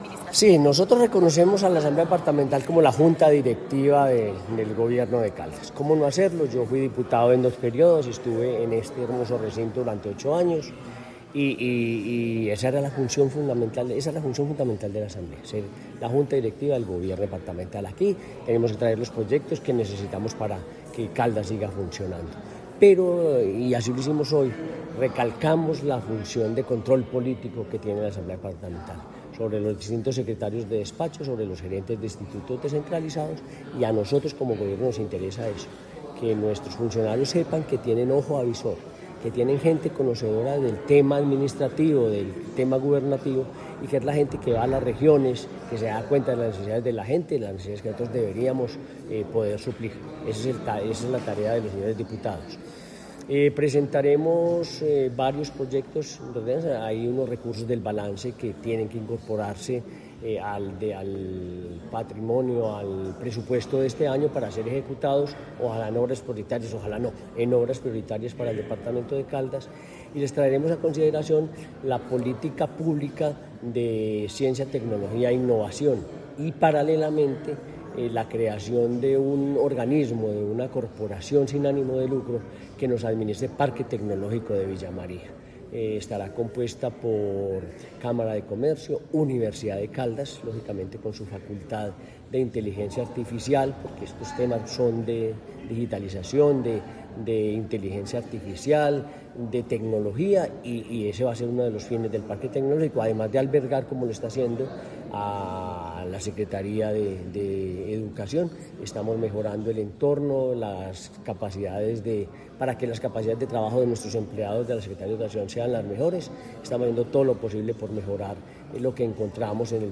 En el recinto de la Asamblea de Caldas, el gobernador de Caldas, Henry Gutiérrez Ángel, instaló oficialmente el primer periodo de sesiones ordinarias, dando apertura a una agenda centrada en el fortalecimiento institucional, el control político y el estudio de iniciativas estratégicas para el desarrollo del departamento.
Henry Gutiérrez Ángel, gobernador de Caldas
Henry-Gutierrez-Angel-Gobernador-de-Caldas1.mp3